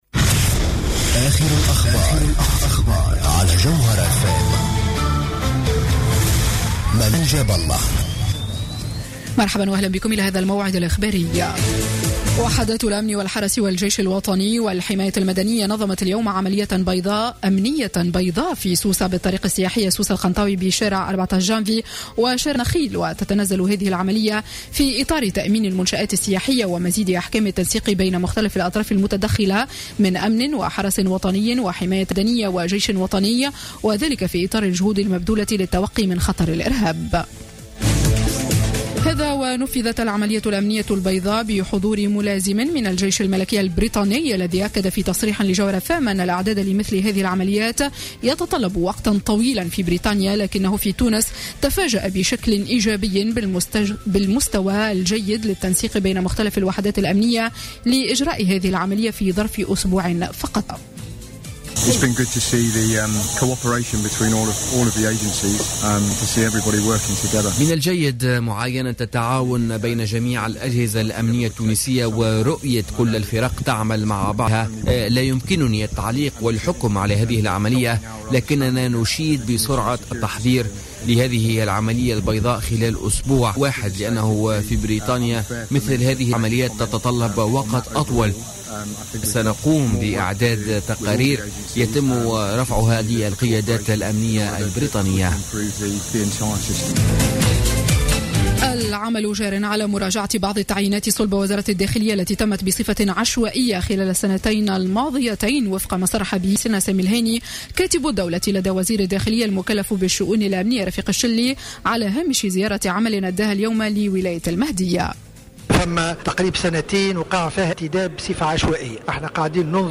نشرة أخبار السابعة مساء ليوم الجمعة 14 أوت 2015